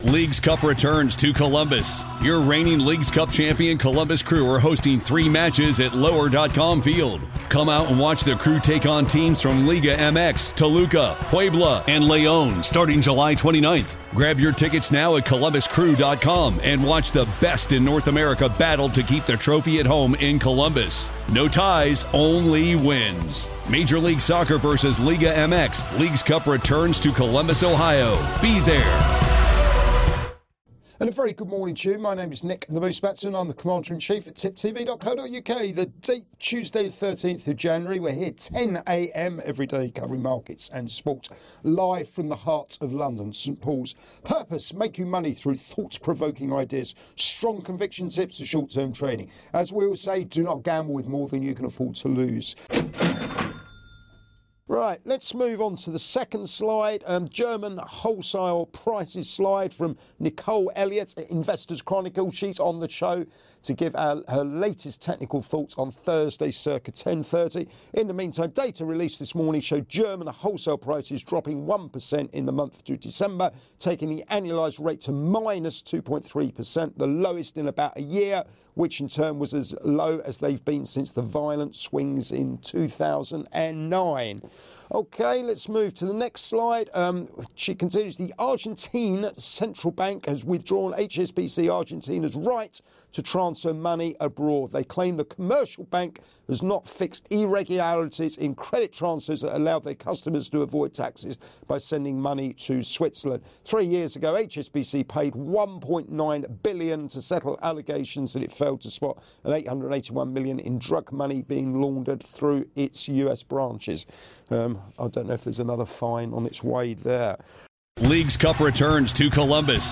Live Market Round-Up & Soapbox thoughts: On today's show